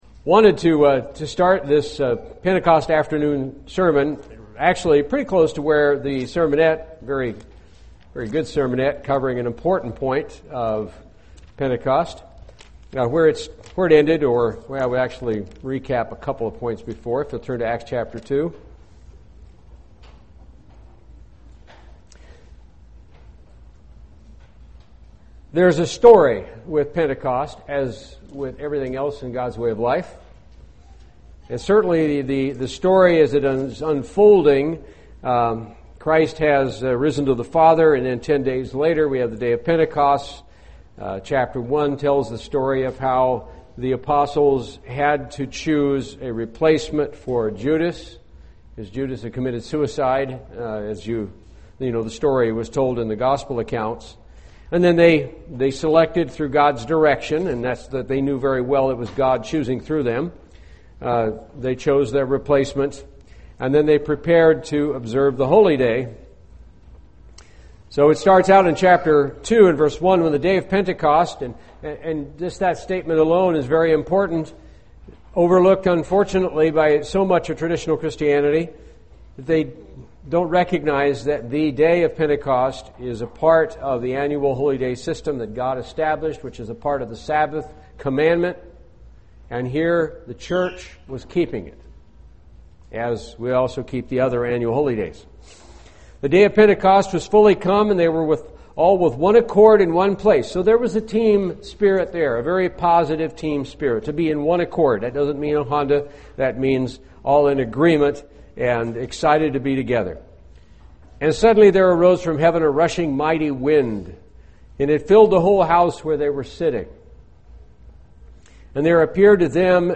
Given in Charlotte, NC
UCG Sermon Studying the bible?